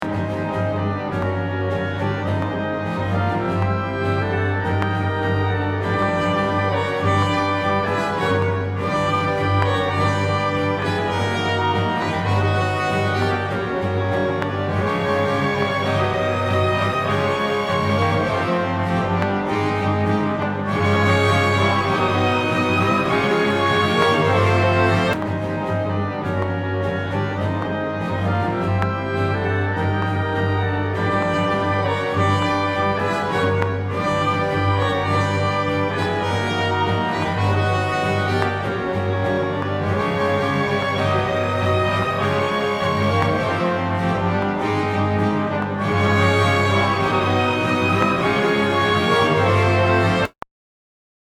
If you listen carefully, you can hear the spikey drums playing somewhere in the background.
This is now very smooth, and much easier to play along to than the spikey piece.
MARS - Lesson 3 - PIECE 2 - slower version.mp3